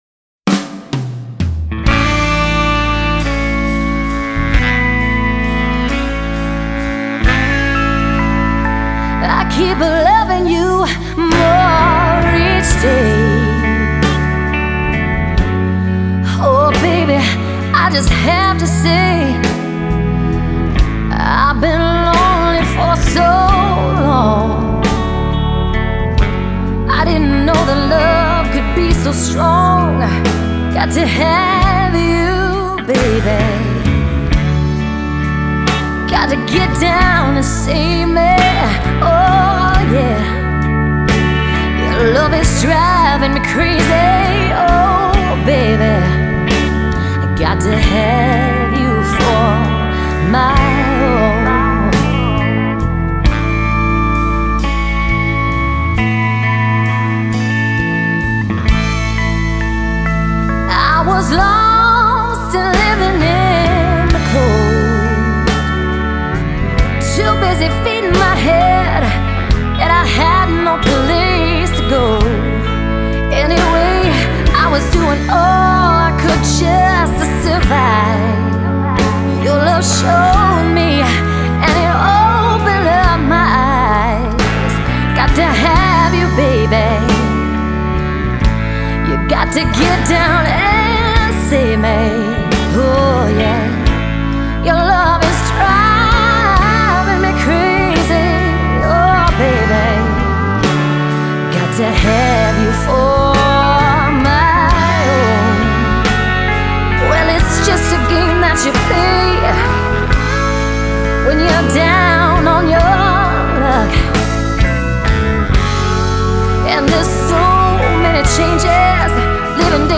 MASTER RECORDINGS - Country